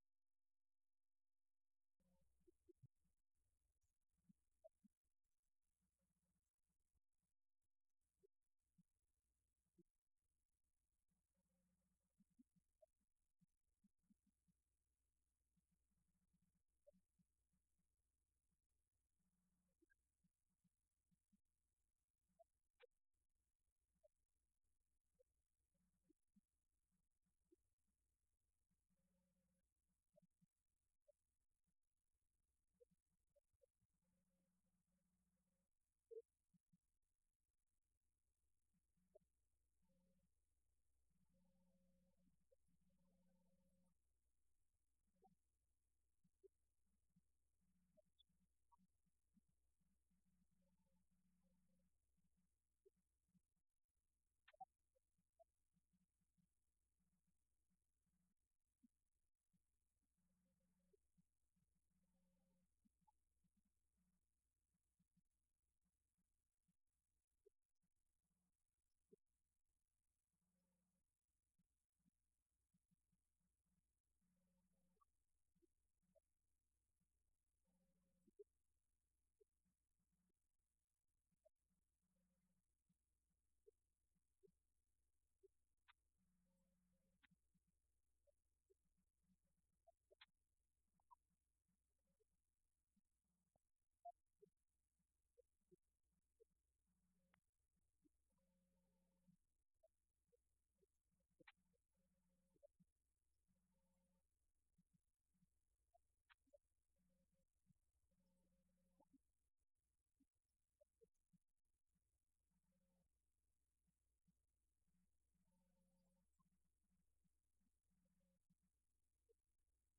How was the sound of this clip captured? Event: 6th Annual Southwest Spiritual Growth Workshop Theme/Title: Arise and Overcome